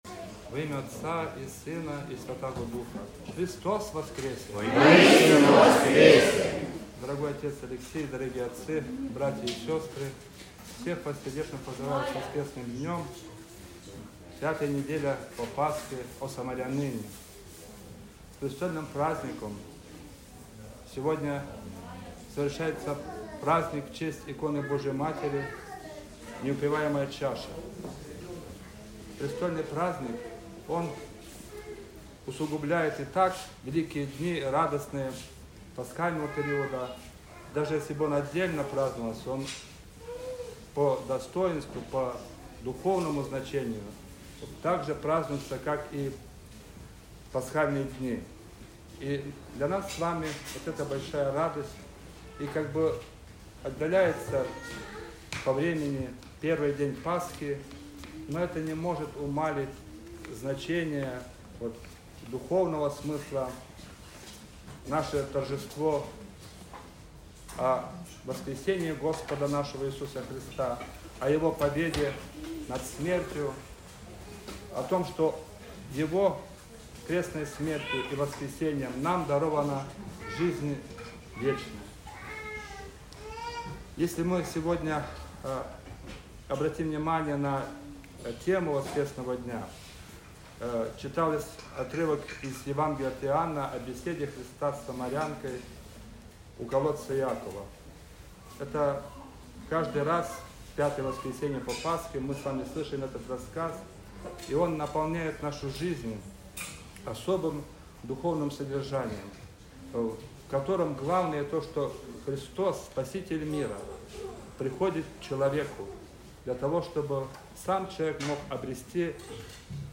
Престольный-праздник.mp3